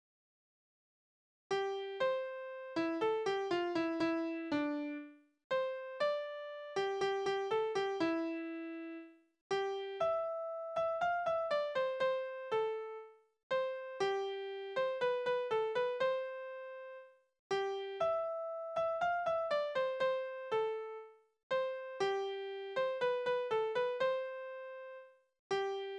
Tonart: C-Dur
Taktart: 4/4
Tonumfang: kleine Dezime
Besetzung: vokal